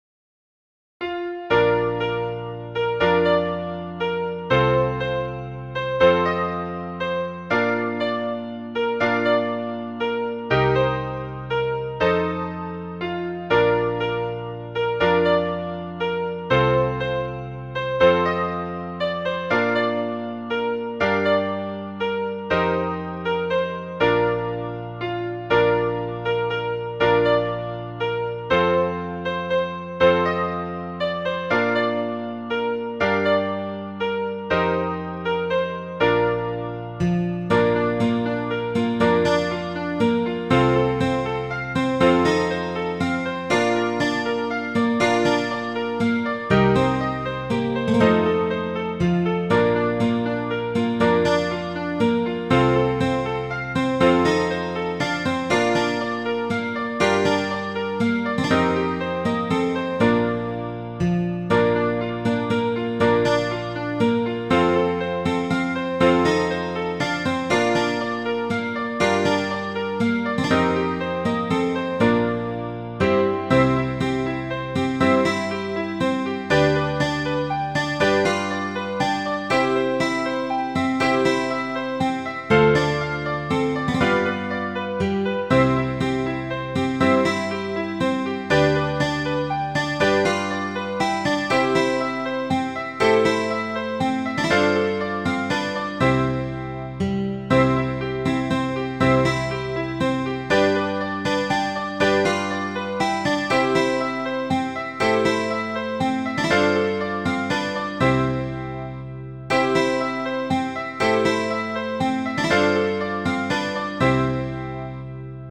cockles.mid.ogg